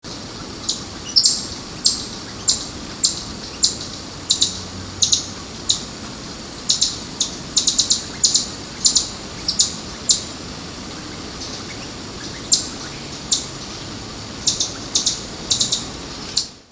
Play call